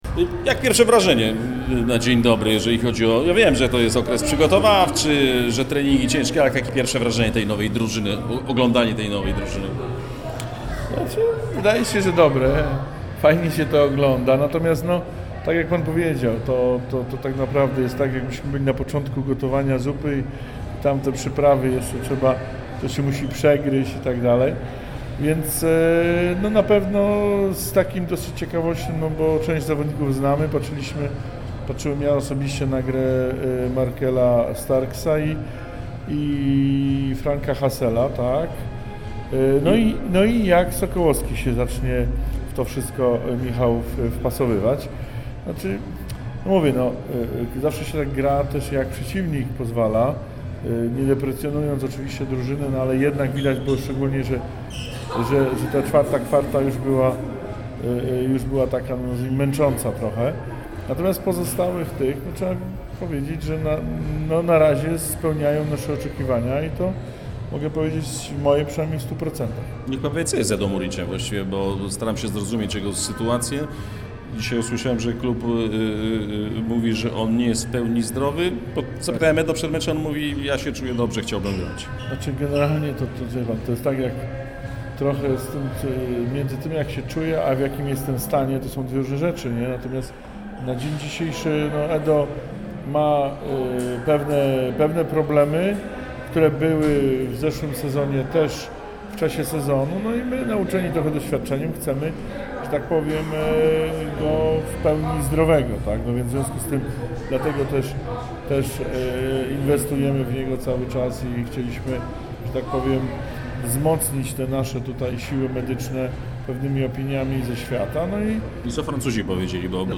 Tymczasem zapraszamy na krótką rozmowę